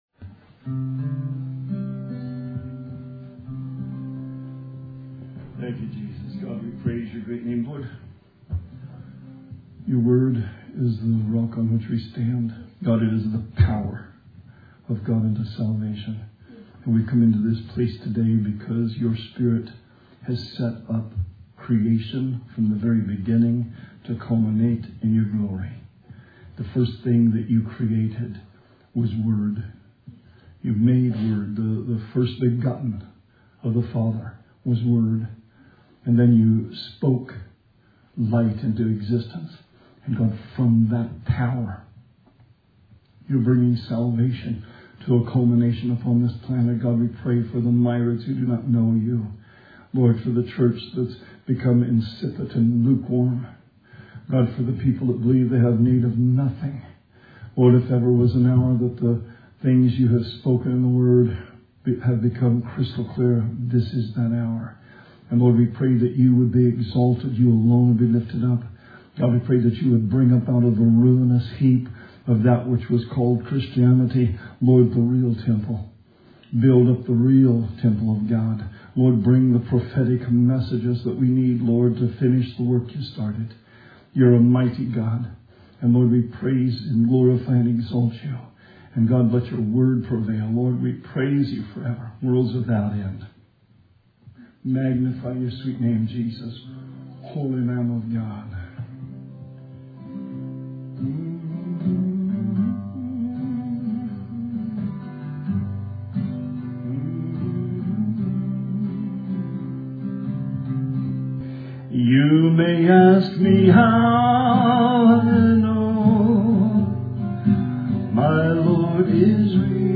Sermon 3/29/20